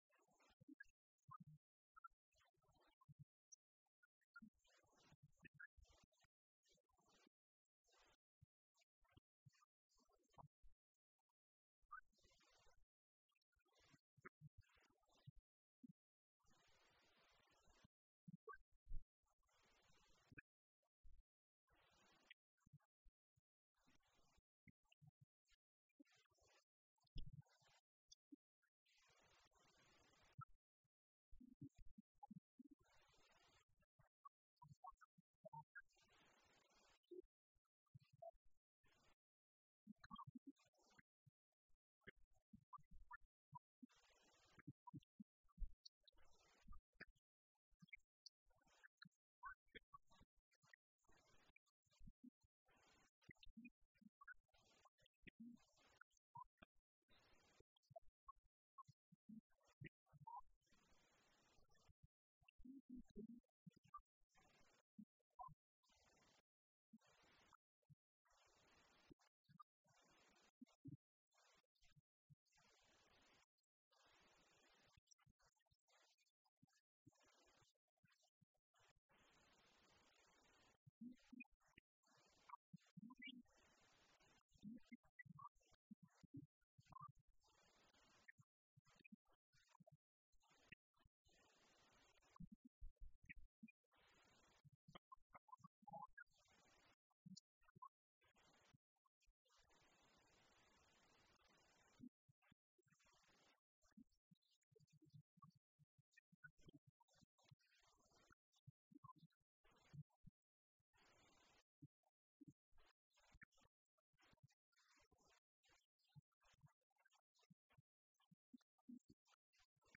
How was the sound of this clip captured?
This sermon was given at the Italy 2015 Feast site.